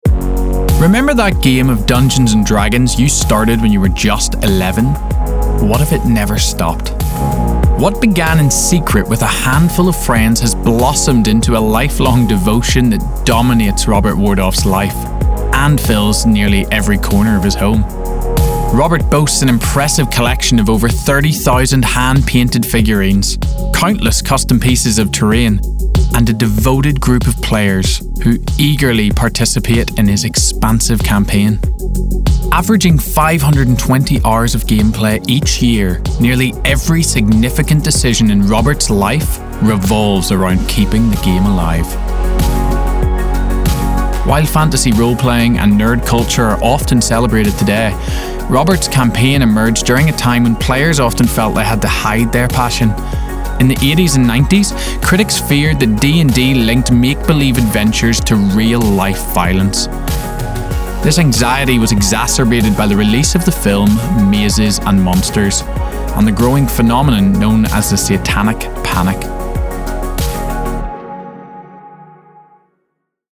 Promo Showreel
Male
Irish
Confident
Upbeat